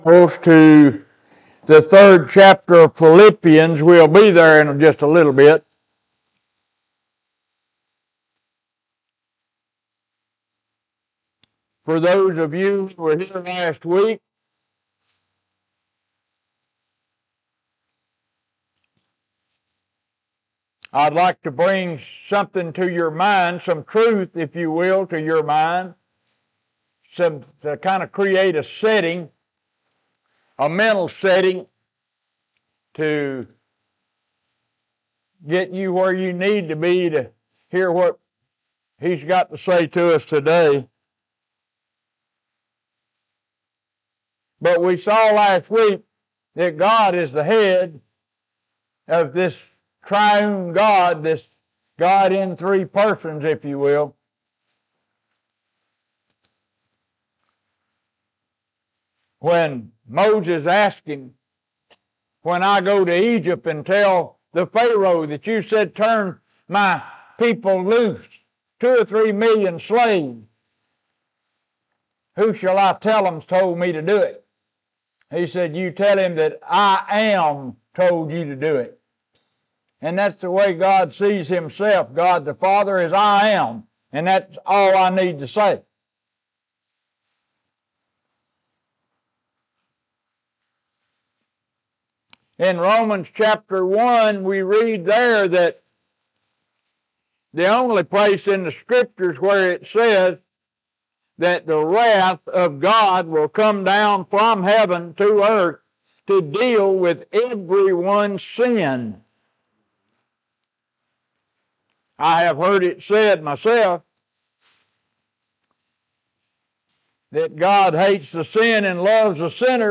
Sermon: Relationship with God and Relationship with Jesus | The Church At Brierfield